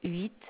noise source hmm transformation
Tech. description: 8khz, 16 bit mono adpcm